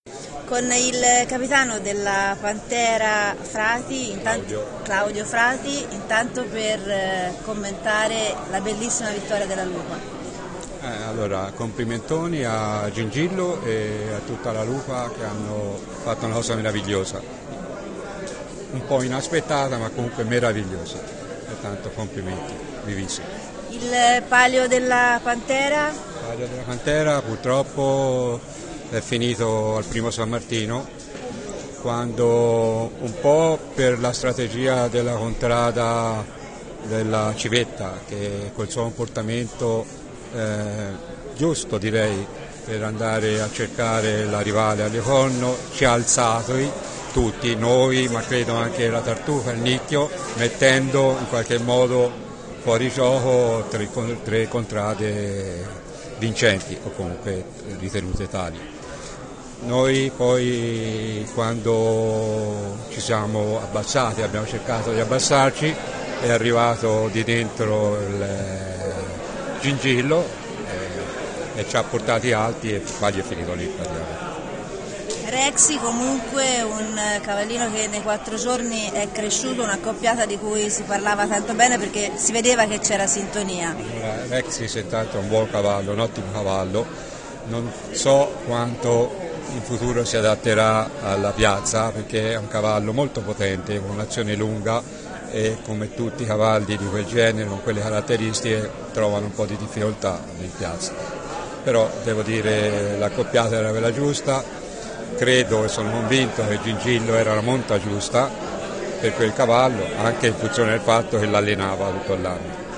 Interviste
Come di consueto, dopo la carriera, abbiamo raccolto i commenti dei capitani delle contrade che hanno partecipato al palio del 16 agosto.